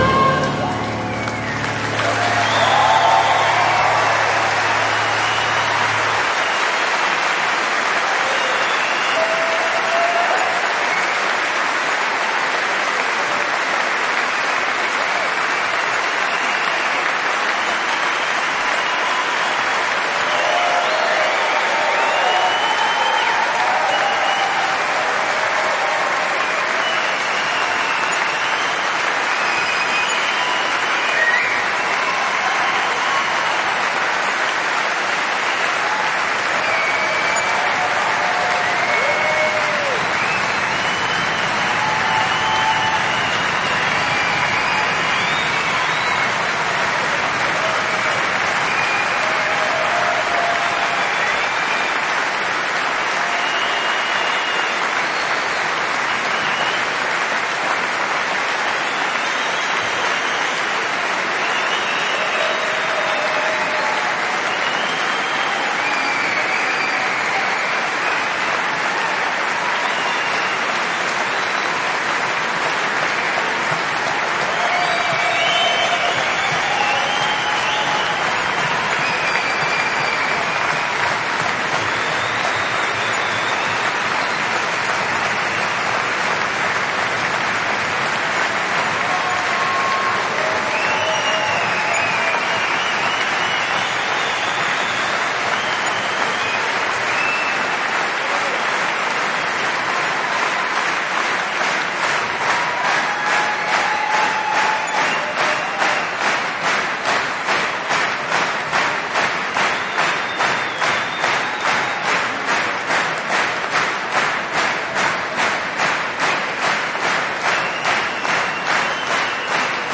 random " applause int large crowd auditorium theater concert show great
描述：掌声大型人群礼堂剧院音乐会表演great.flac
Tag: 礼堂 大型 INT 显示 人群 掌声 戏剧 音乐会